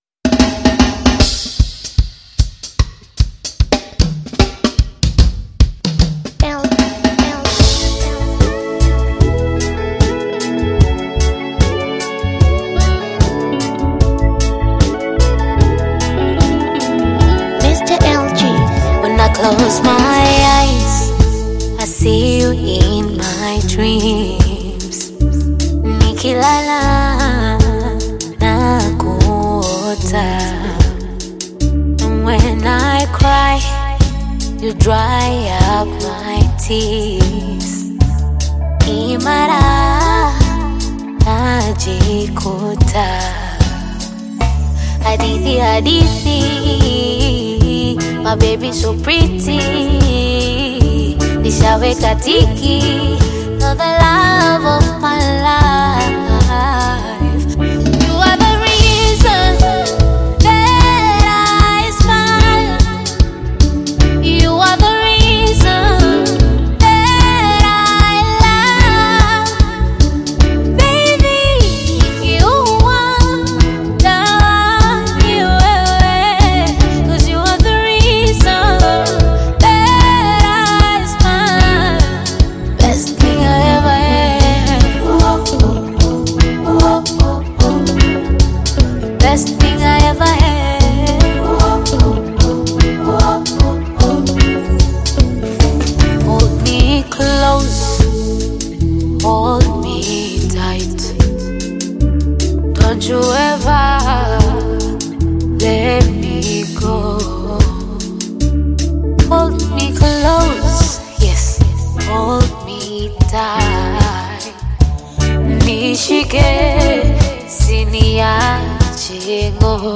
sweet voice
mix of Bongo Flava, Afro-pop, and R&B music